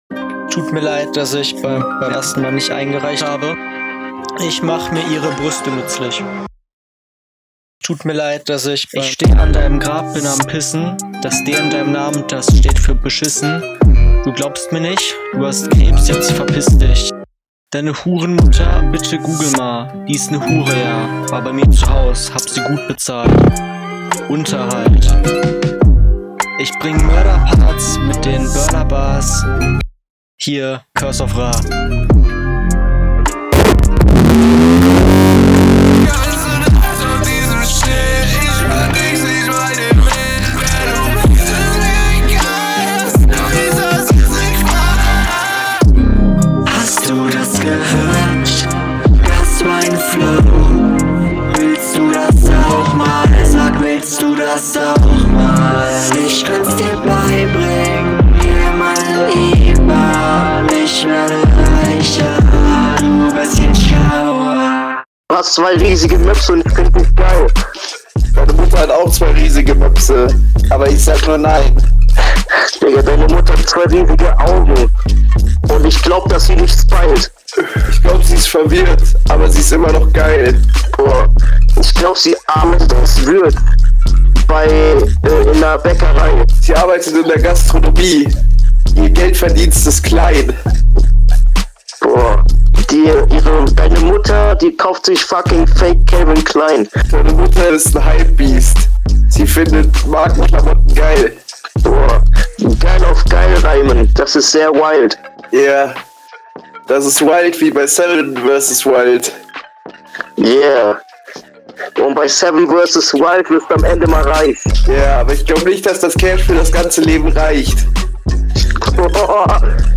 Super Hook, sonst pure Scheiße, ich hassliebe das